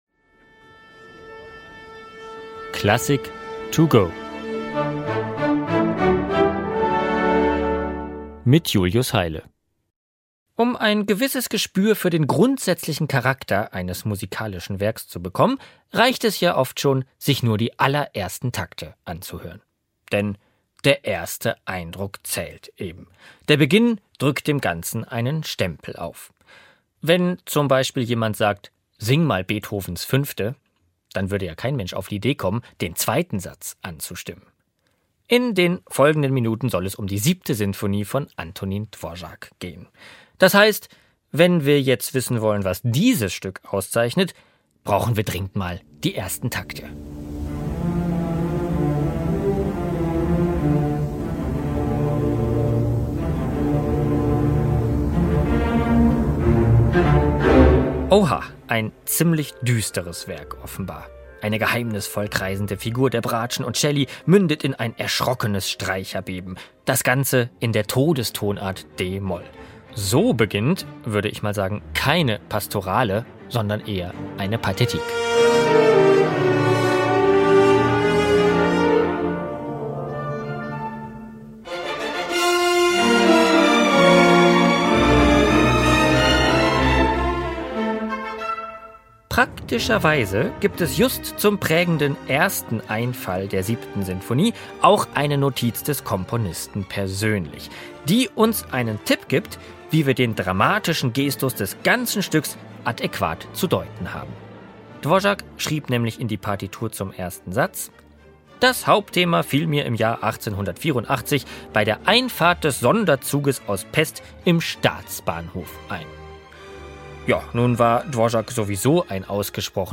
"Klassik to Go" - die digitale Werkeinführung zum Download!